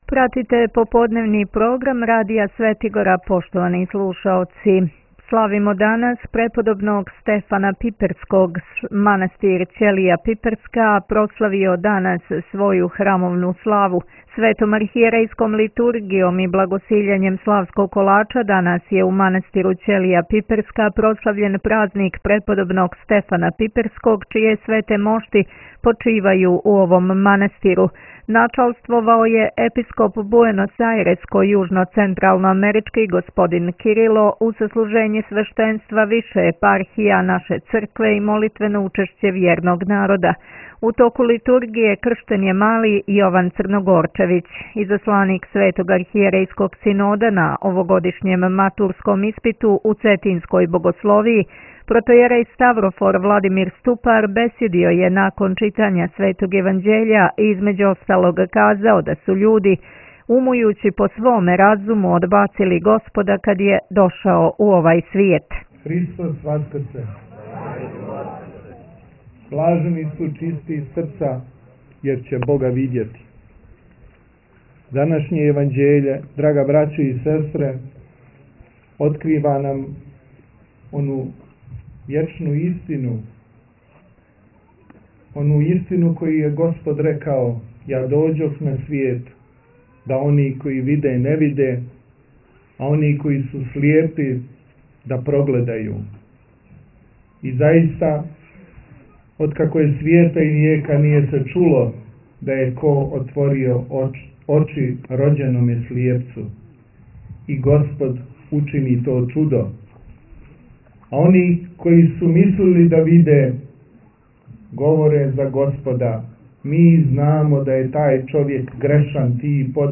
Митрополит Амфилохије служио у манастиру Ћелија пиперска